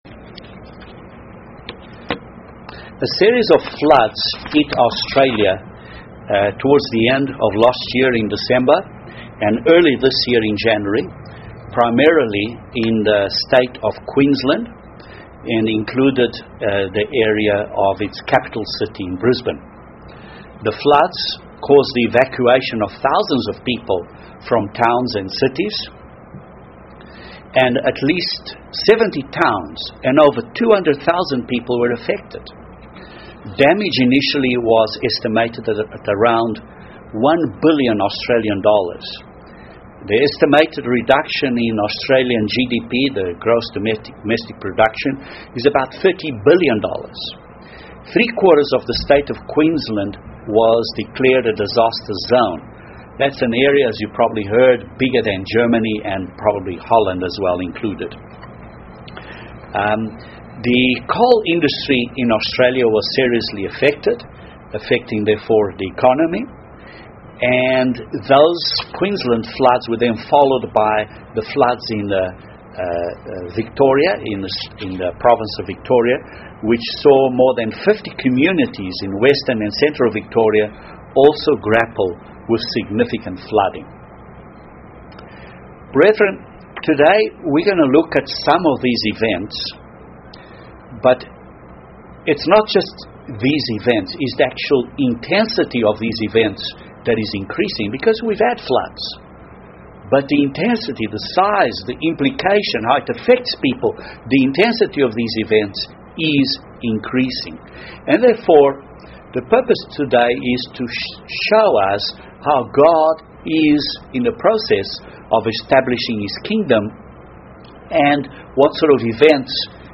Could the combination of this severe weather, pressures on the worldwide food supply, financial difficulties, wars, and other factors, lead to major disease epidemics? This sermon highlights how these events seem to tie in with the 4 horsemen of Revelation and to the sequence and ever